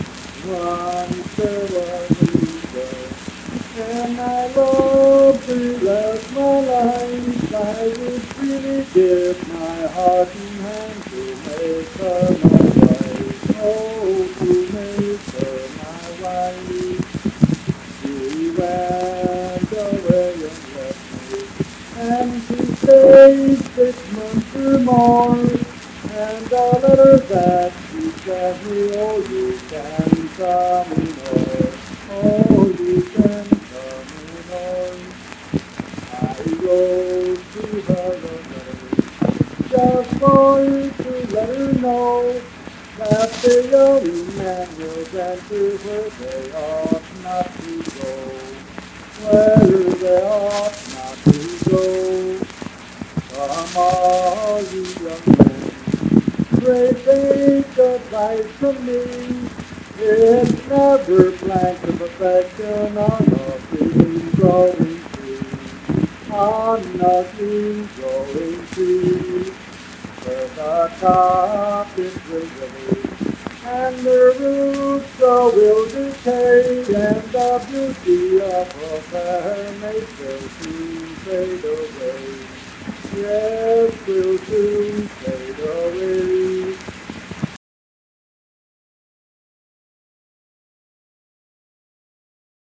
Unaccompanied vocal music performance
Verse-refrain 5(5w/R).
Voice (sung)